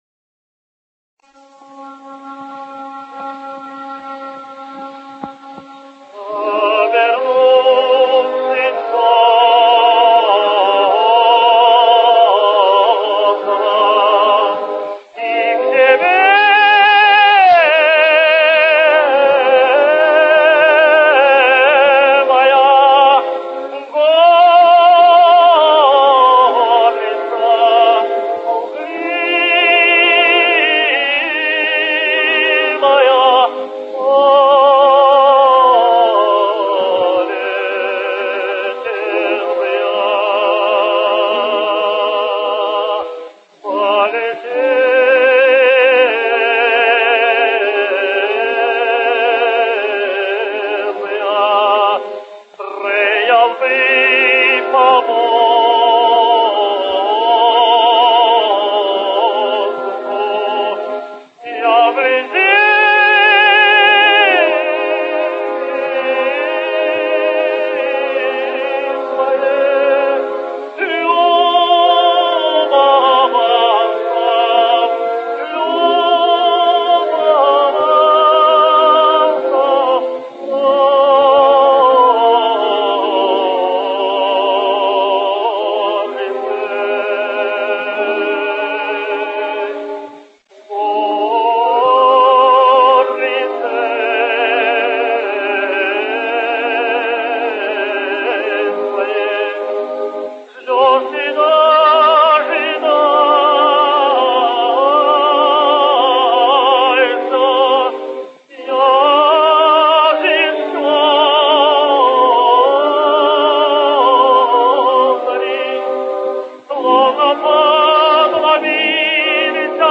His voice had a range up to high F.
Andrej Labinskij singsDemon: